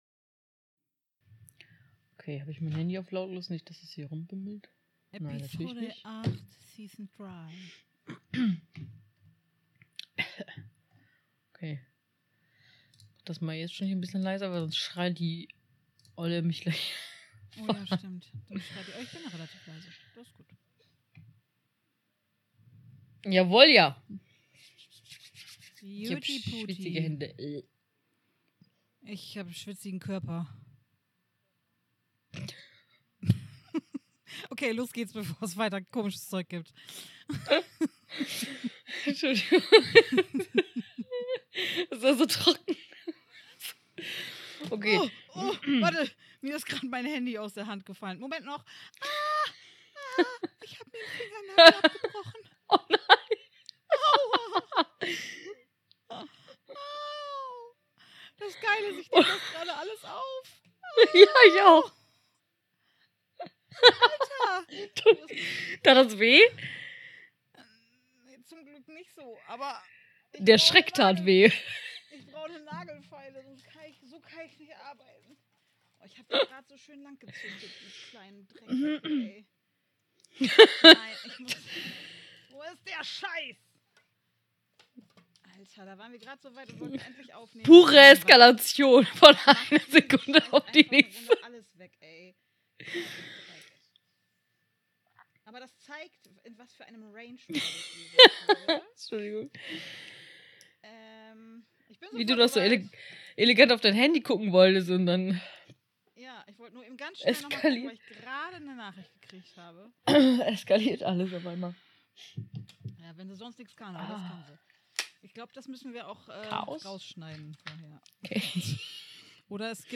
Heute wird es etwas lauter...